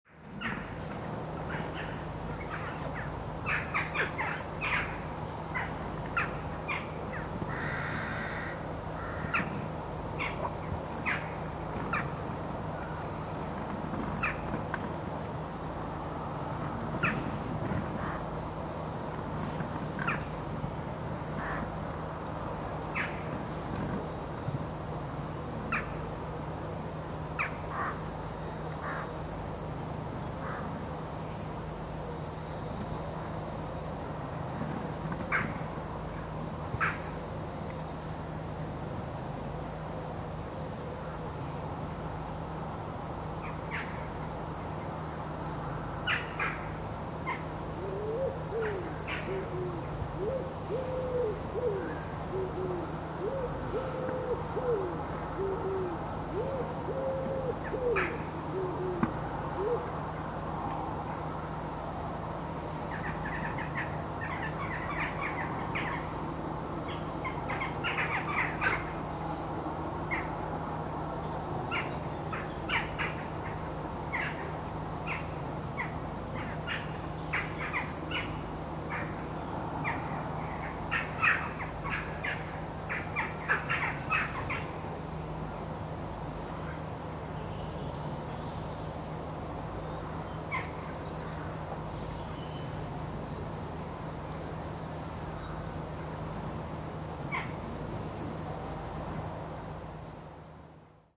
Dohle Ruf
Die Dohle hat einen hellen, kurzen Ruf, oft wie „kjack“.
Dohle-Ruf-Voegel-in-Europa.mp3